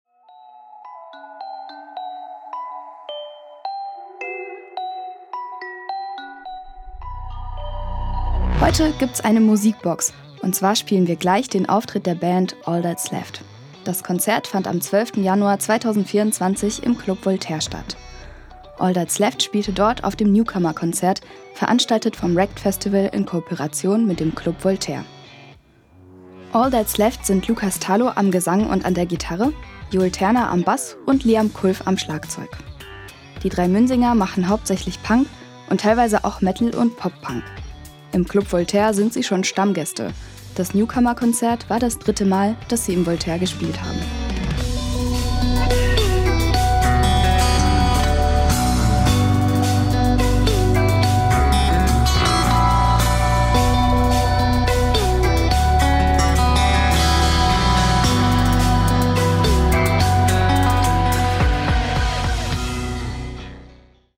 am Gesang und an der Gitarre
am Bass